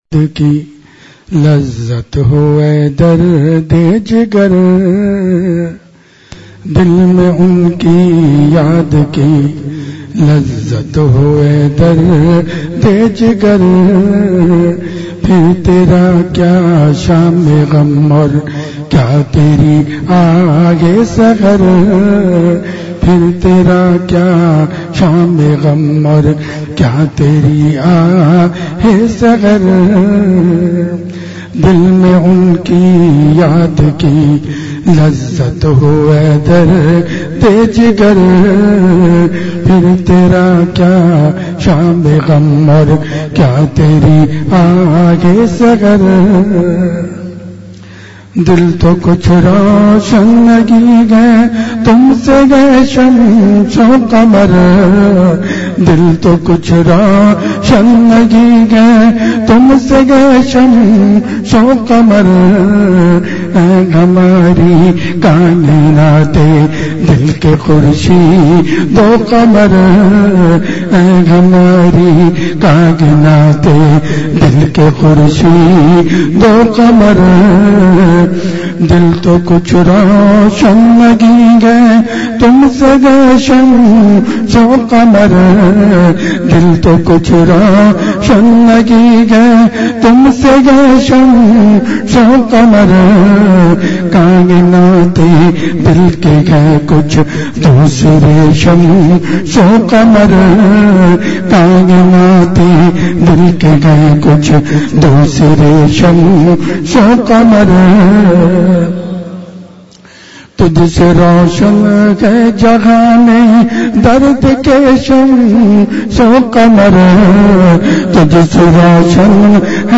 اصلاحی مجلس کی جھلکیاں